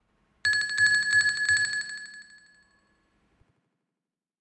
Serene ding ding
bell ding dong ring sound effect free sound royalty free Sound Effects